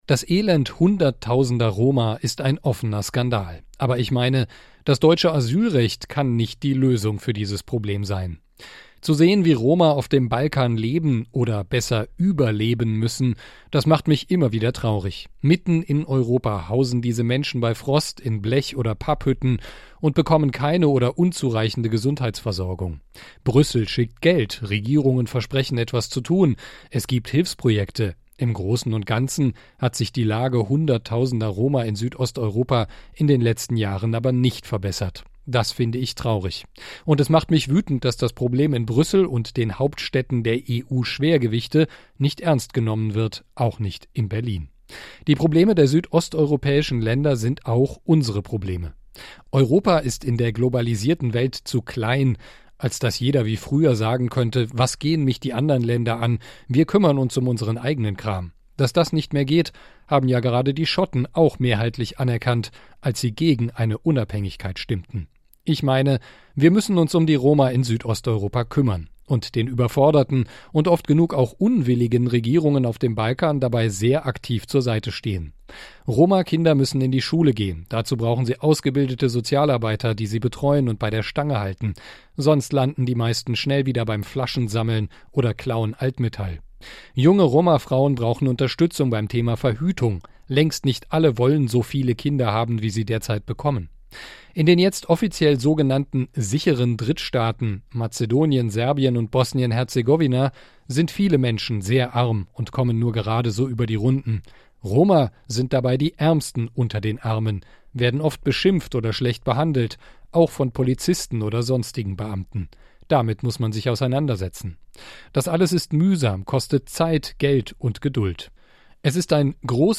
KOMMENTAR: Das Elend der Roma auf dem Balkan geht uns alle an!